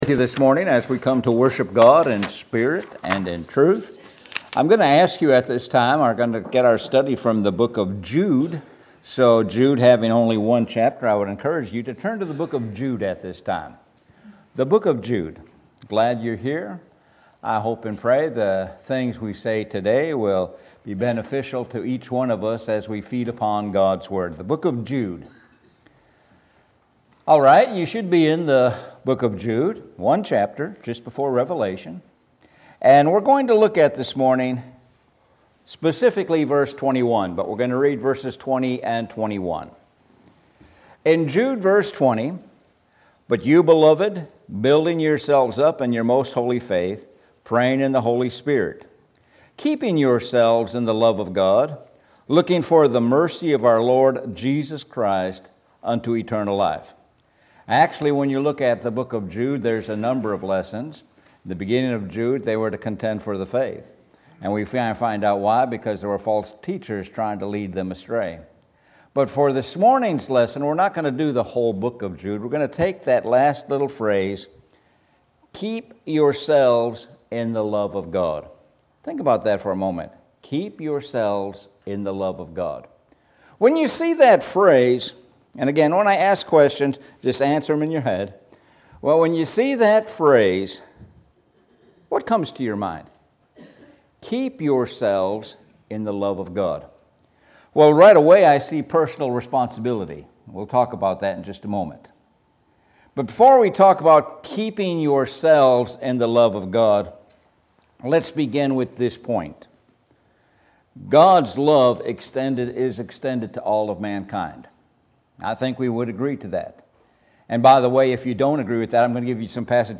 Sun AM Sermon